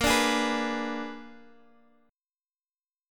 Bb7b9 chord